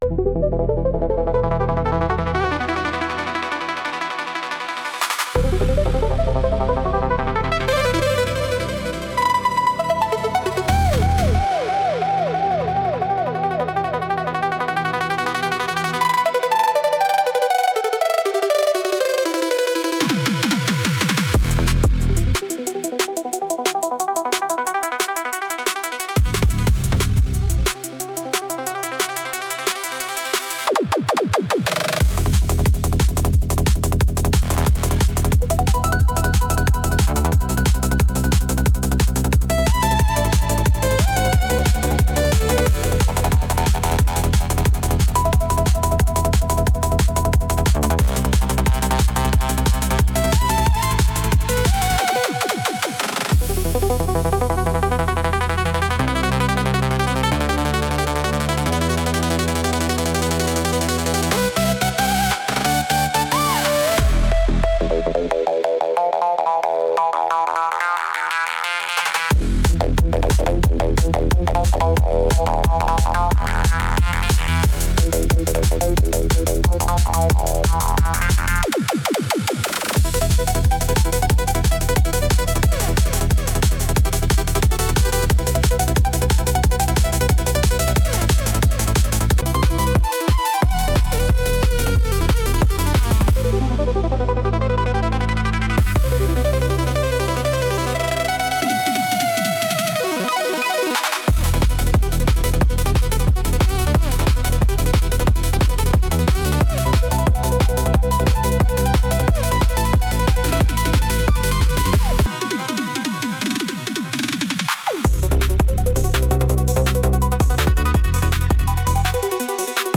There are no lyrics because it is an instrumental.